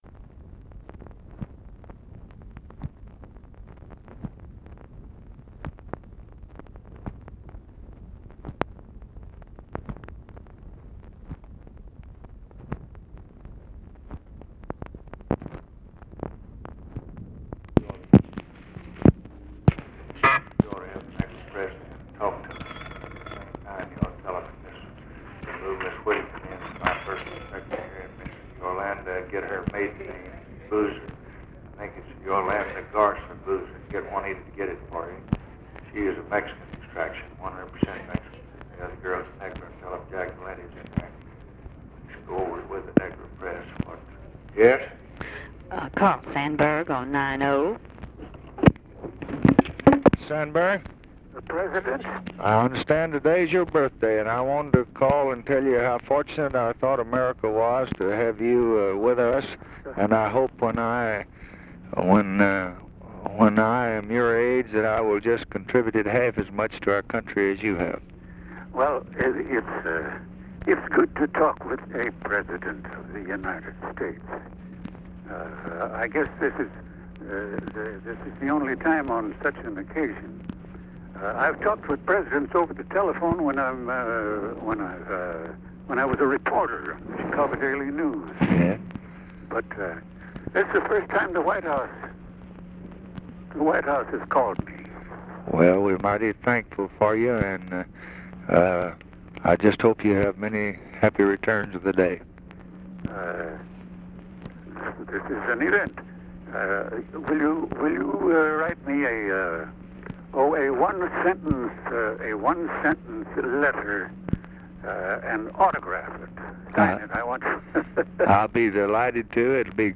Conversation with OFFICE CONVERSATION, January 6, 1964
Secret White House Tapes